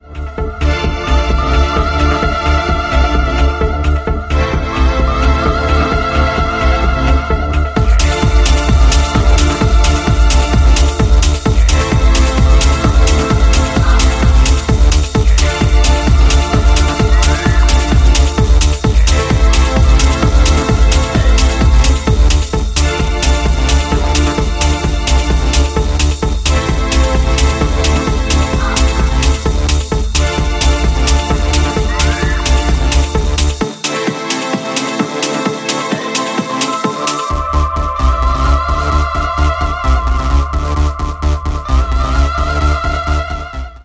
B面と対比するかのような荘厳なコードで魅せるピークタイム路線の内容です。